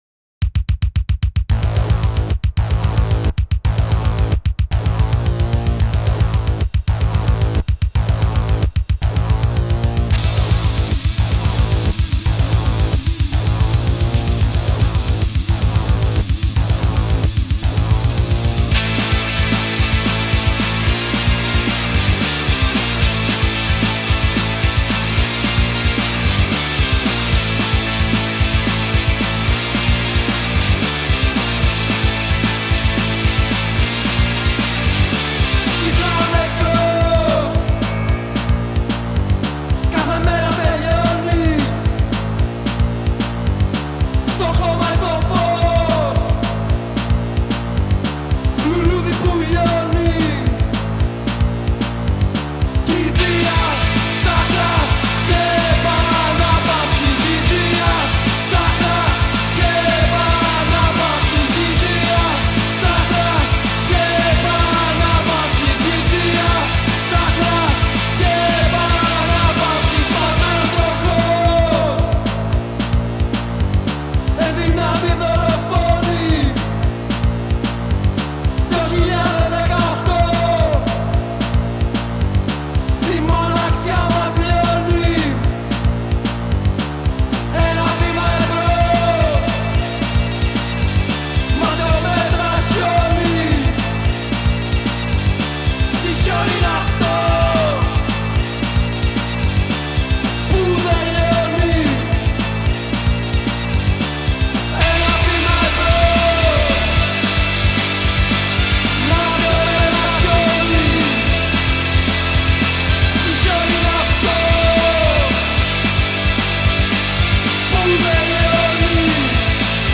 Ελληνόφωνη, σκοτεινή/φωτεινή, ποπ, γουεηβ, νταρκ, πανκ, ρέηβ